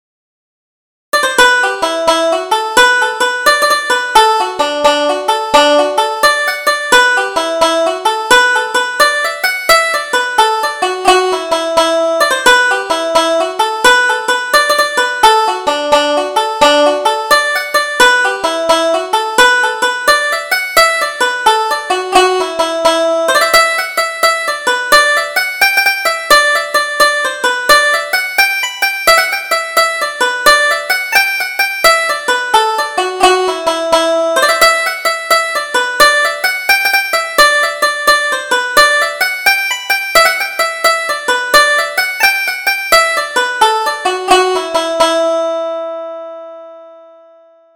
Double Jig: The Man in the Moon